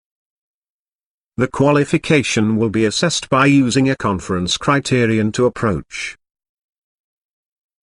You will hear a sentence. Type the sentence or you can write on paper or notepad and check the answer by clicking on show answer after every dictation.